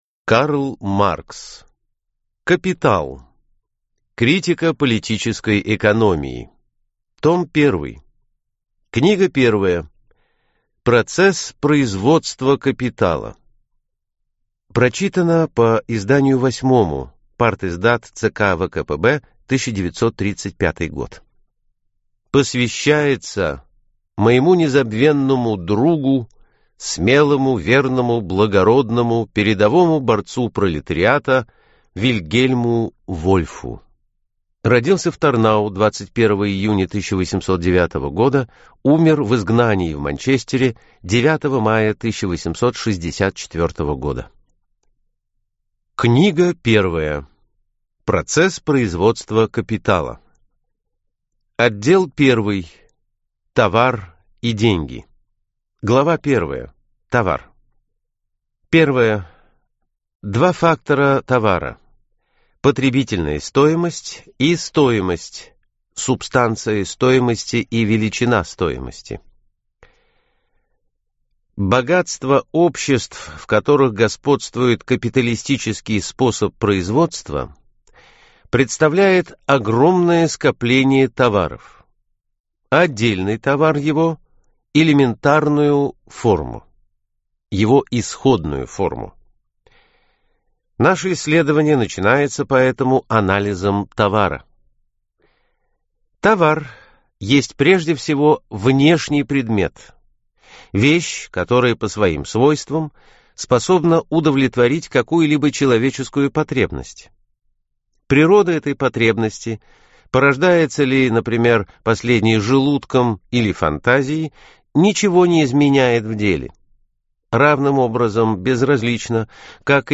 Аудиокнига Капитал | Библиотека аудиокниг